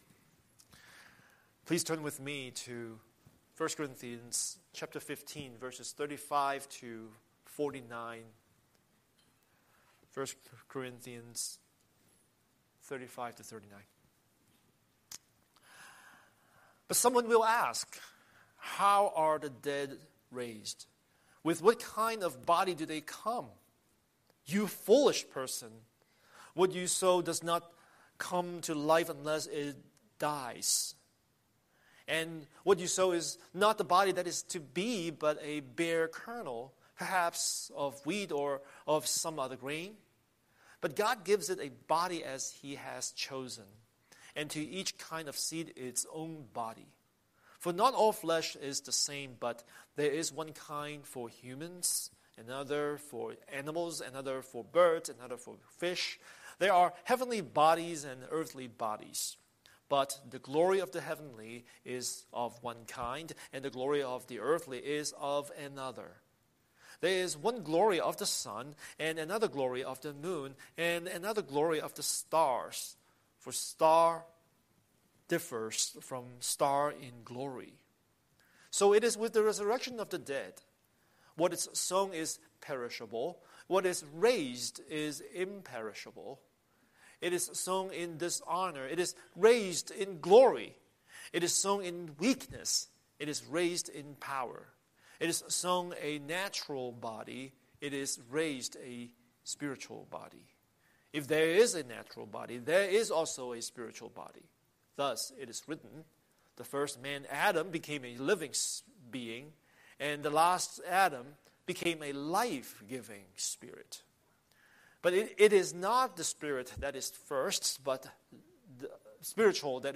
Scripture: 1 Corinthians 15:35–49 Series: Sunday Sermon